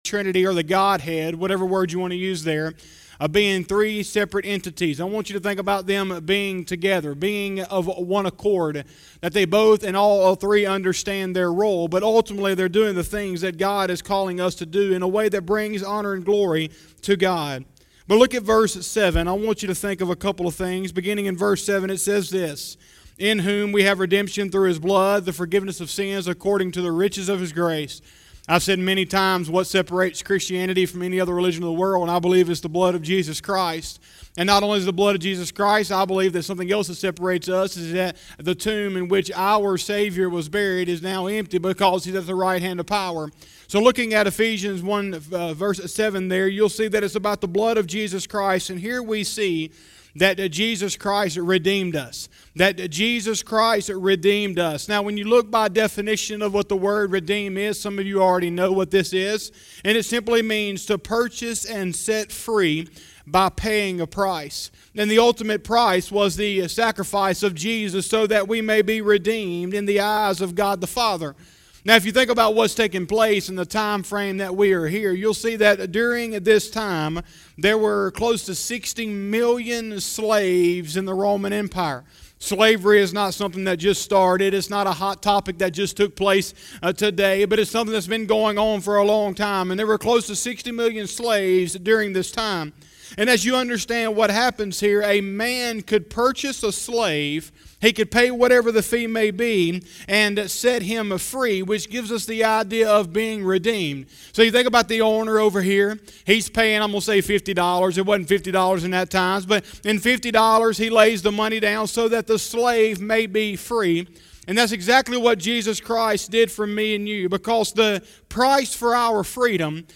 07/05/2020 – Sunday Morning Service